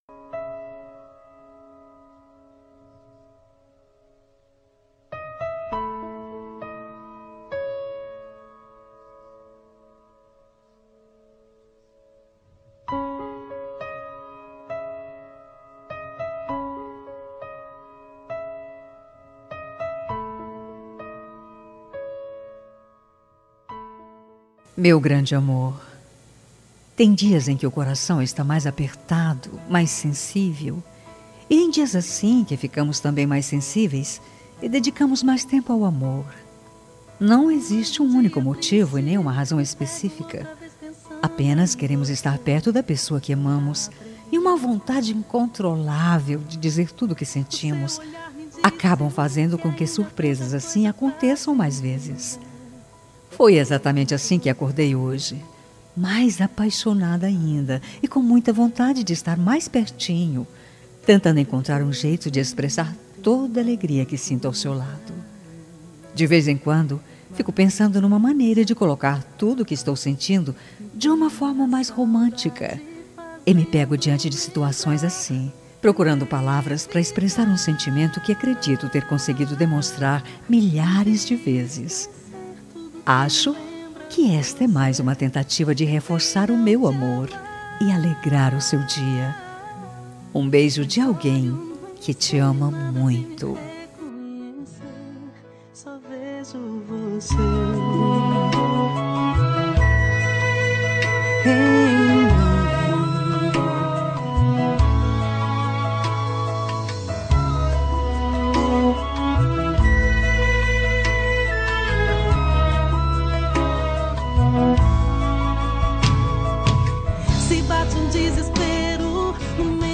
Telemensagem Romântica para Marido – Voz Feminina – Cód: 9069